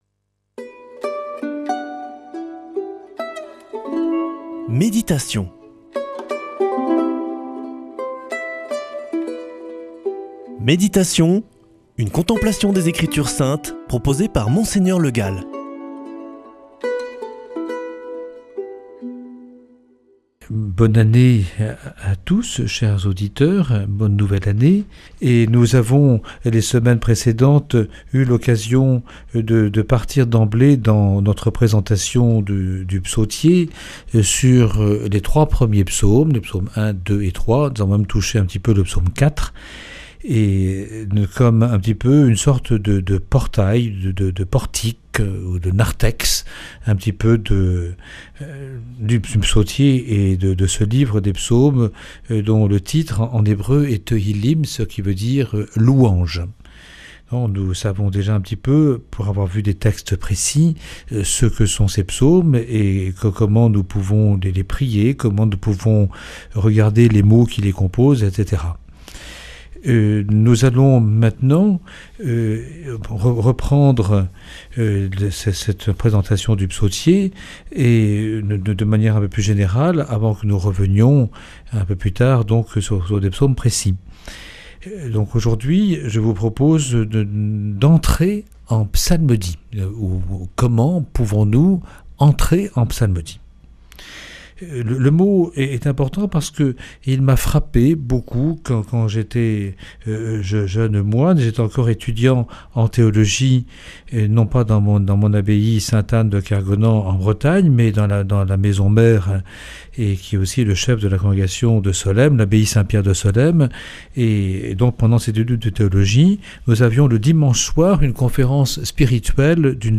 Méditation avec Mgr Le Gall
Monseigneur Le Gall